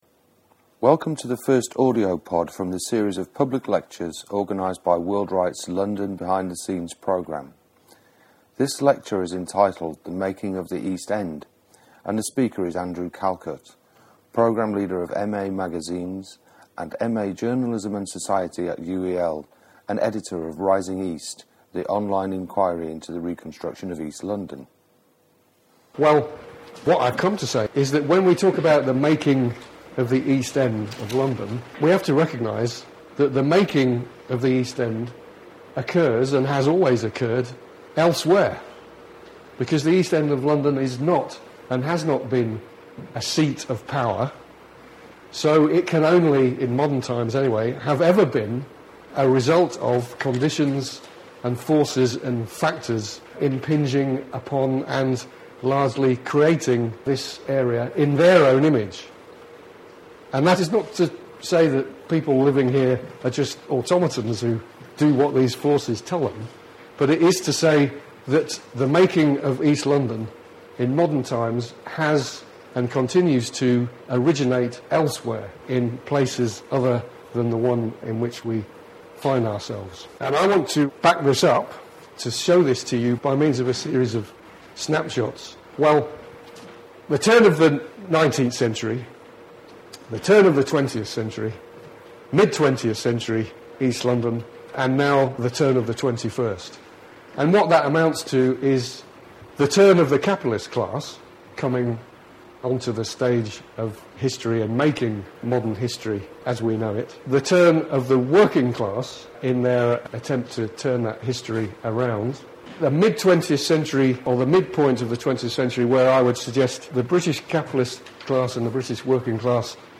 Public Lectures: The making of the East End